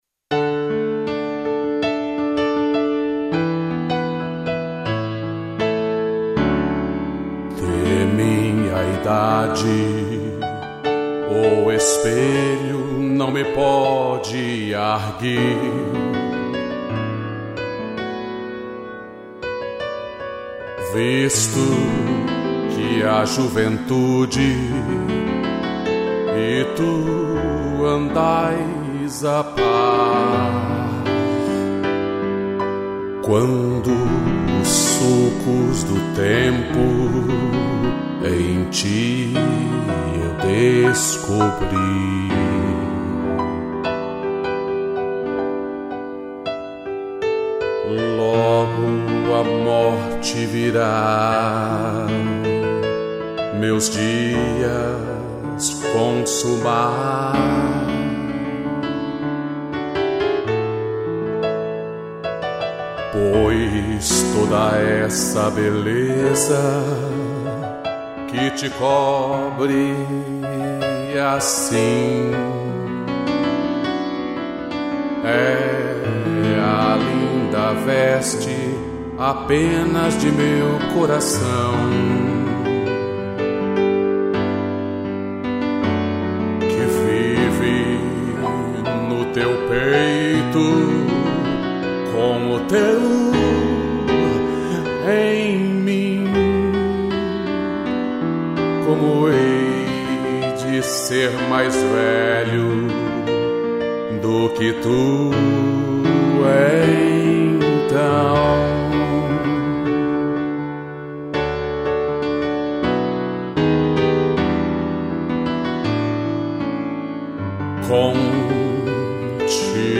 Voz e violão
piano